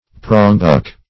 Prongbuck \Prong"buck`\, n. (Zool.)